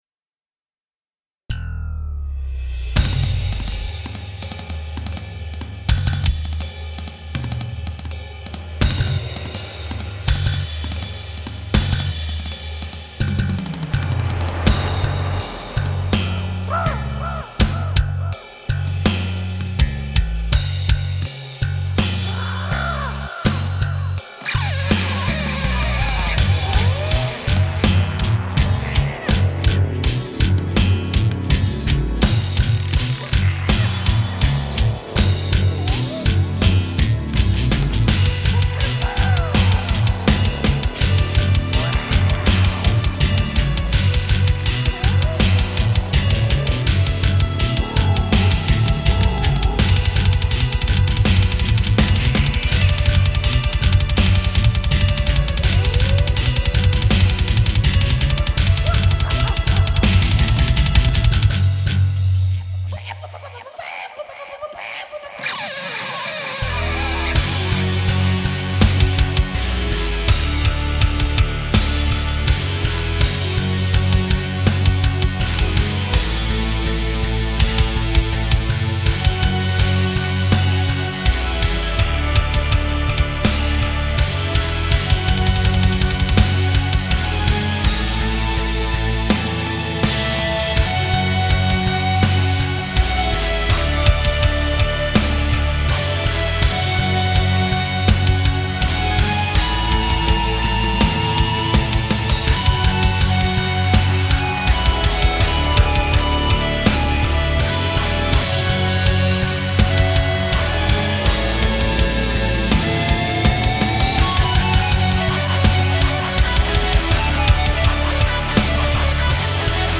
Just though of sharing a WIP/demo for a new, unreleased attept of song here. I don't know how to define this in terms of genre, but I'd say it's some kind of "synth tribal metal" pretending to represent some kind of "army of birds defending the forest from the human invasors" ... or whatever, I dunno.